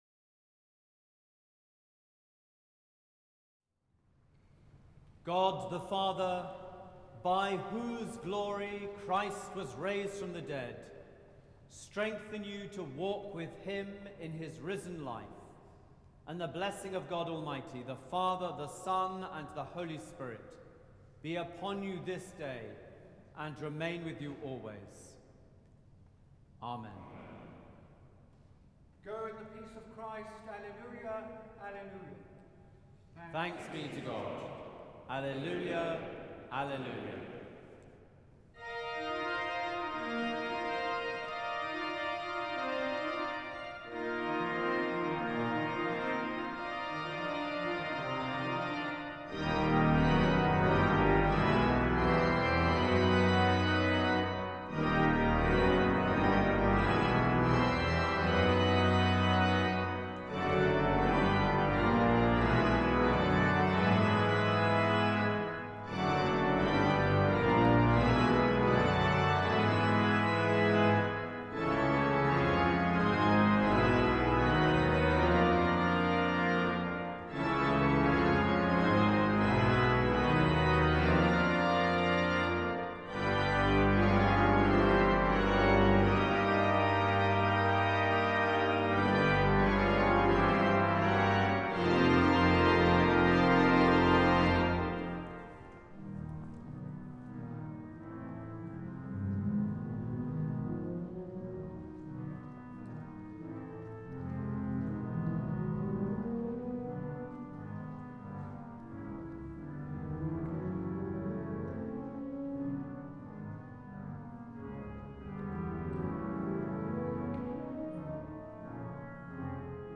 25 Blessing and Voluntary: Intrada
Collection: Sung Eucharist - 24 April 2016